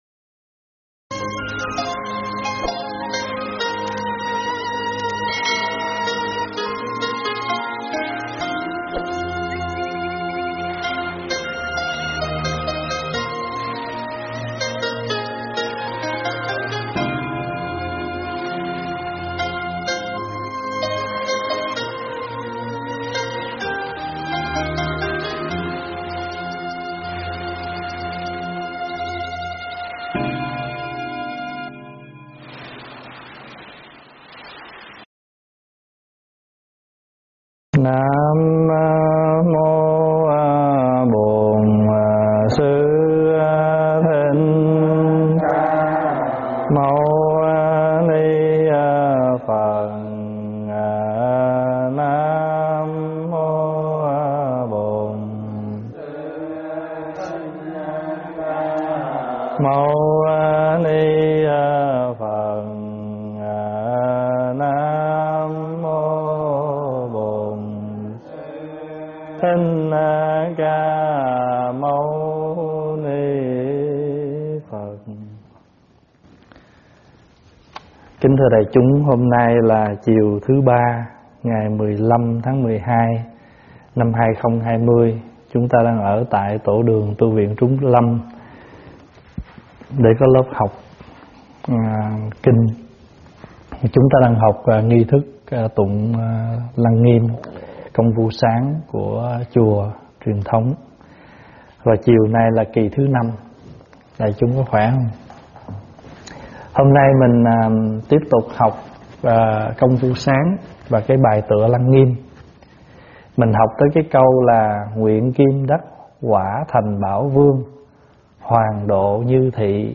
Thuyết pháp Sen Nở Ban Mai 5
giảng tại Tv Trúc Lâm